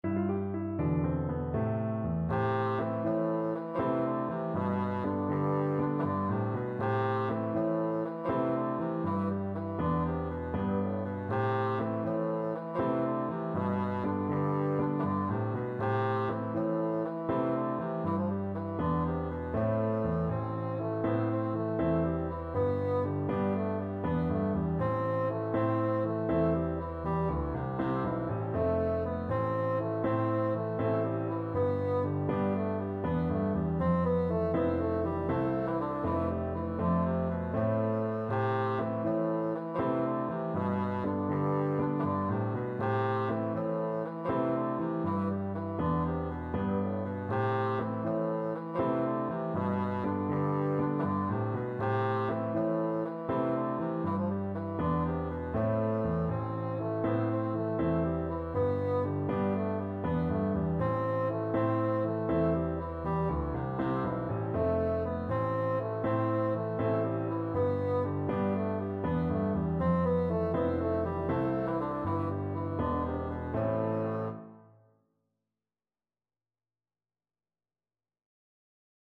Bassoon
9/8 (View more 9/8 Music)
G3-C5
A minor (Sounding Pitch) (View more A minor Music for Bassoon )
Traditional (View more Traditional Bassoon Music)